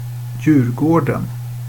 Djurgården (pronounced [ˈjʉ̌ː(r)ɡɔɳ] or [ˈjʉ̂ːrˌɡoːɖɛn]
Sv-djurgården.ogg.mp3